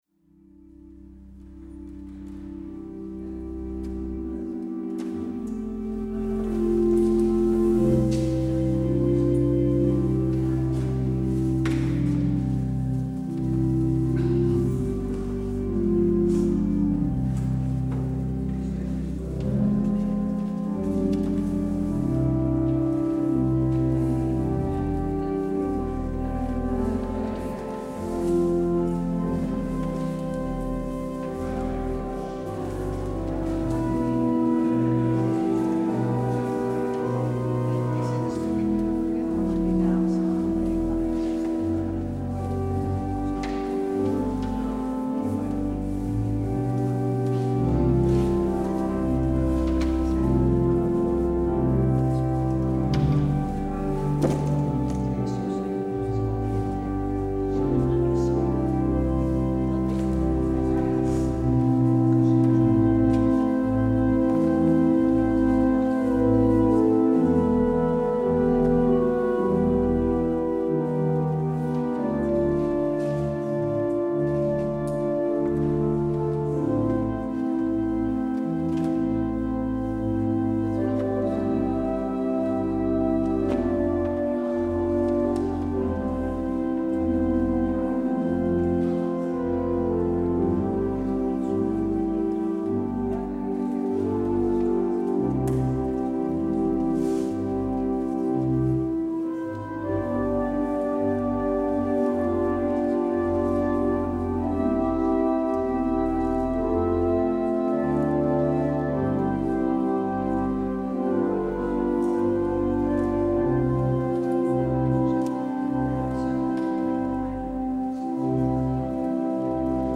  Luister deze kerkdienst hier terug: Alle-Dag-Kerk 17 december 2024 Alle-Dag-Kerk https